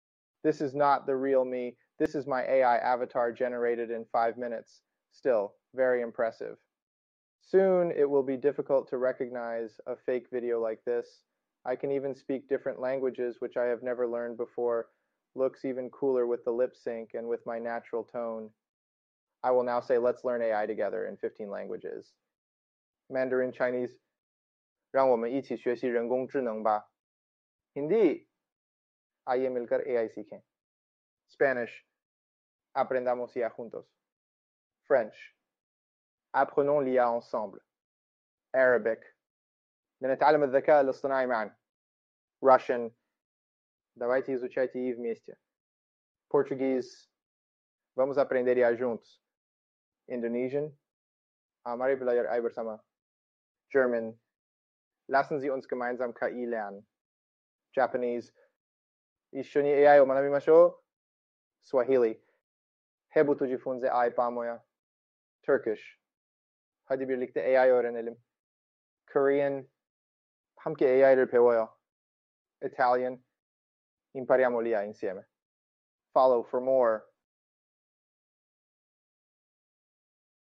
The video and voice are completely AI-Generated 🤯 I used Gen AI to create My digital twin.